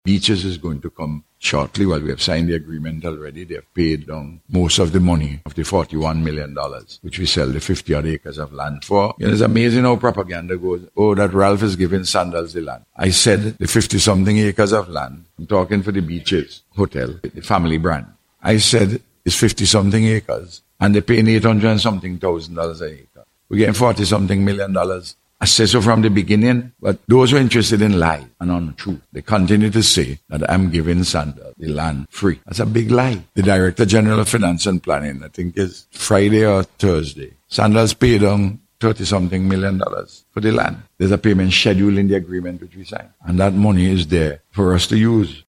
He made this statement during a ceremony which was held last week, for the Grand Opening of the Garifuna C.I.P Lounge at the Argyle International Airport.